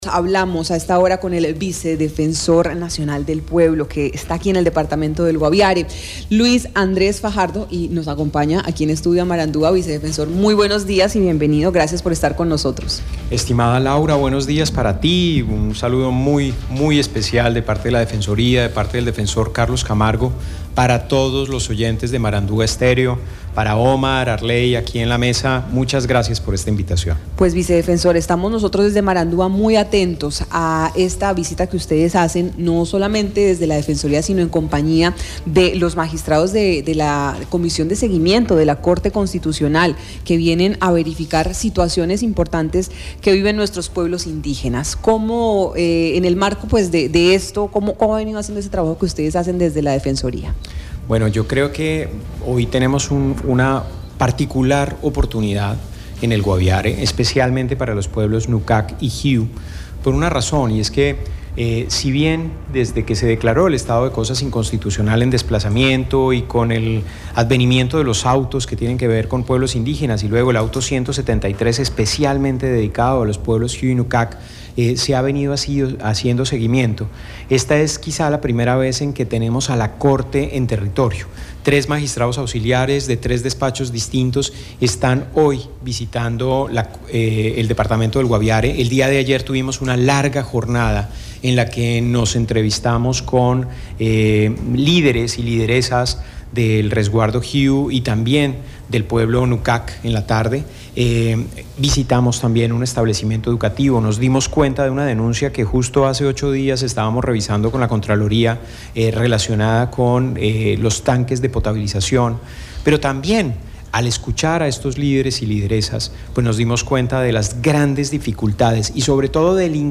En entrevista con Marandua Noticias, Luis Andrés Fajardo, vicedefensor del Pueblo, quien hace parte de la comisión que junto a la Corte Constitucional realizan una visita al Guaviare para verificar la situación de los pueblos indígenas Jiw y Nukak, aseguró que se ha podido conocer el incumplimiento en los autos que se han proferido en […]